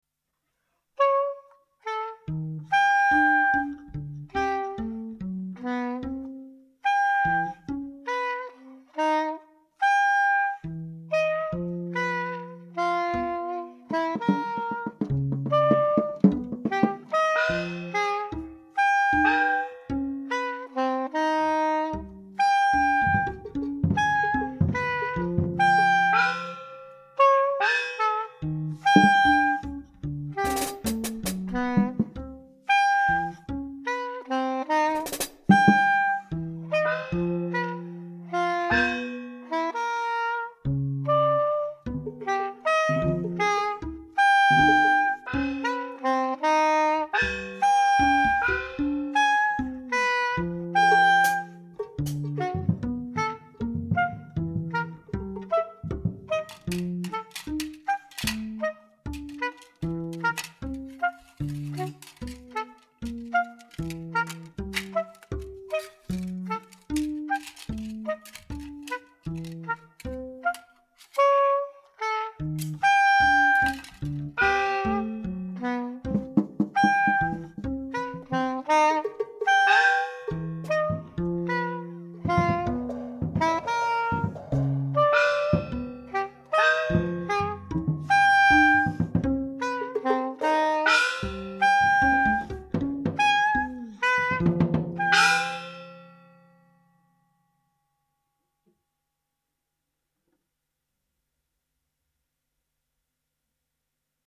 alto & soprano sax
double bass
drums
Recorded at Radiostudio Zürich
Die Musik besitzt Poesie und feinen Humor.